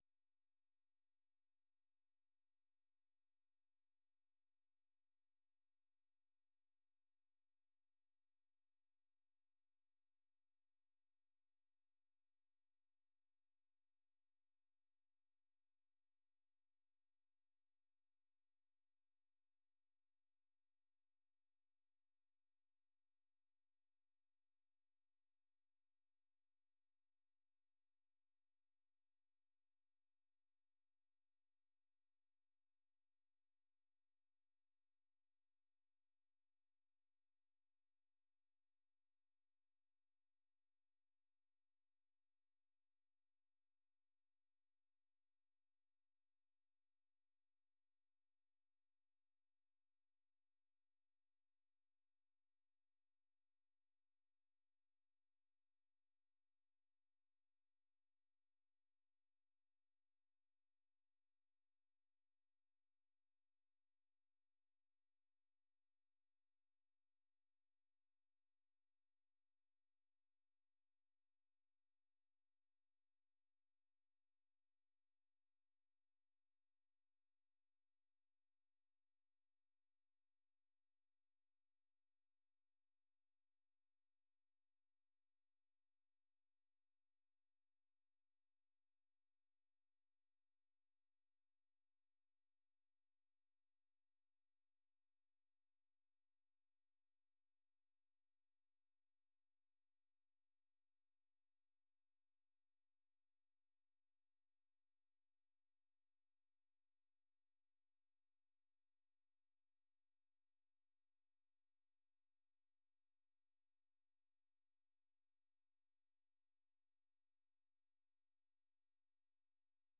VOA 한국어 방송의 아침 뉴스 프로그램 입니다. 한반도 뉴스와 함께 밤 사이 미국과 세계 곳곳에서 일어난 생생한 소식을 빠르고 정확하게 전해드립니다.